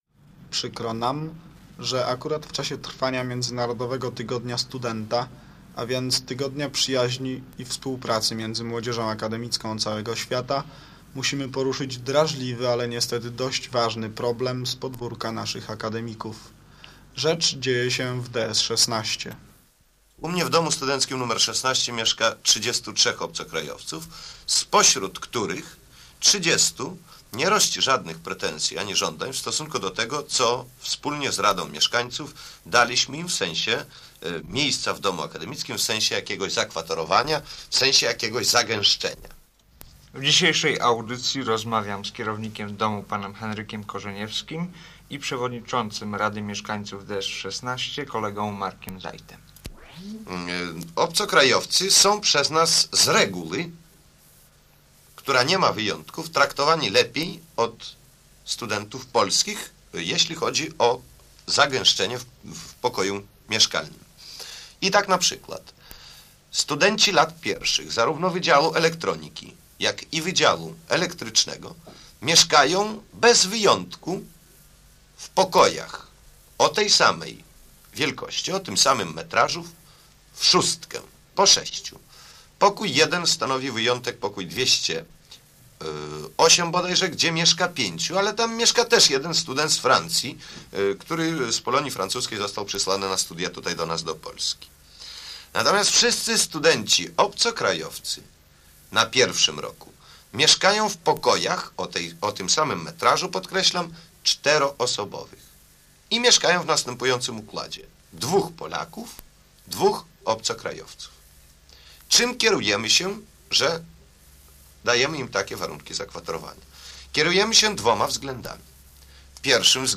Rozmowa o problemach studentów zagranicznych mieszkających w akademikach